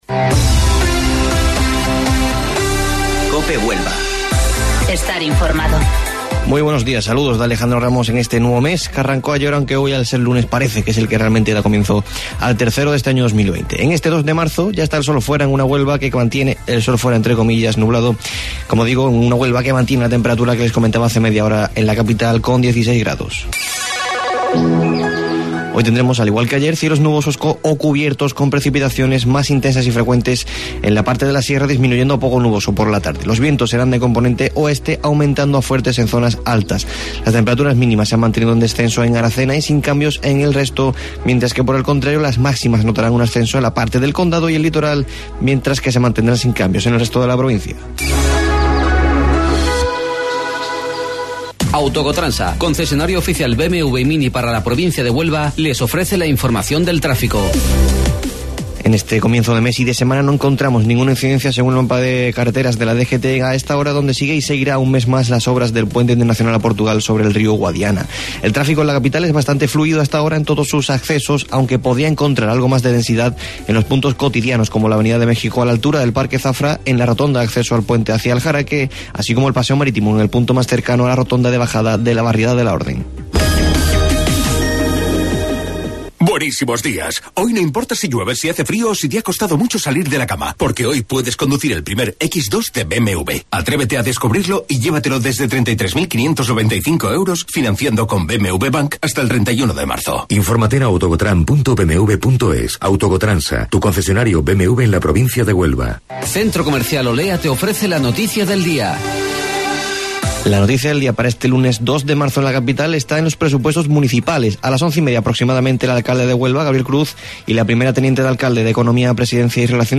AUDIO: Informativo Local 08:25 del 2 de Marzo